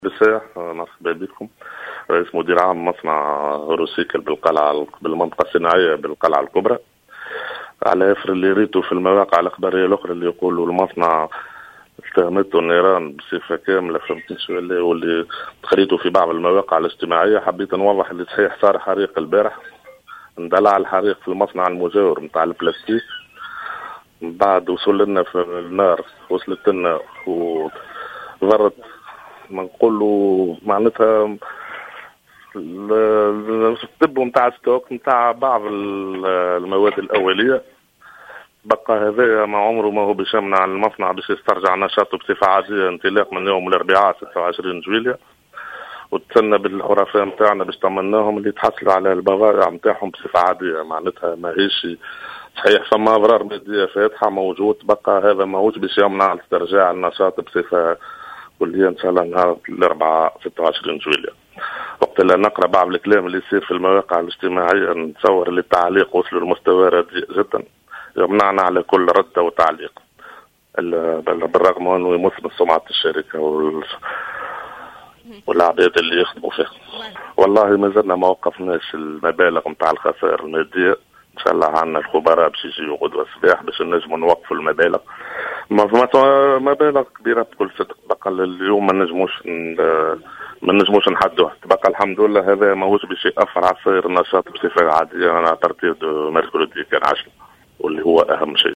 وأوضح في اتصال هاتفي بـ"الجوهرة أف أم" أن حريق أمس امتد إلى جزء من المصنع وطال مخزنا لبعض المواد الأولية فقط.